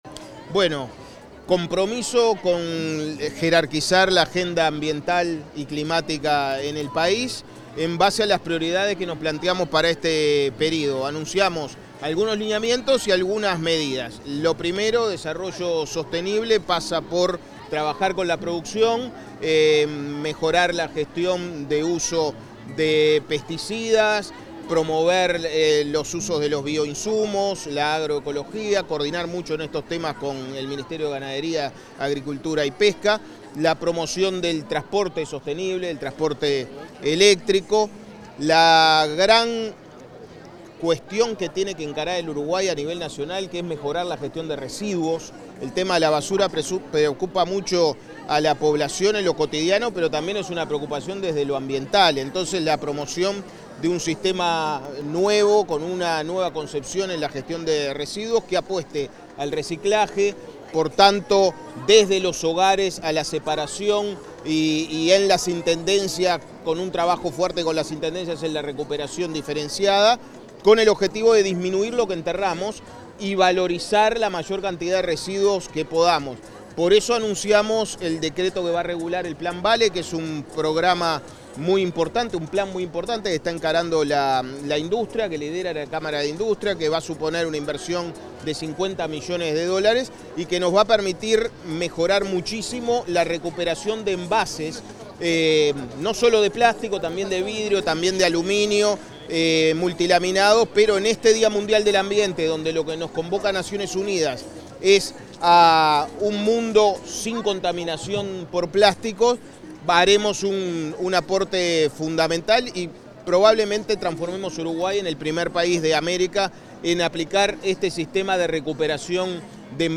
El ministro de Ambiente, Edgardo Ortuño, fue entrevistado por medios de prensa tras la ceremonia de inauguración de la 4.ª edición de la Expo Uruguay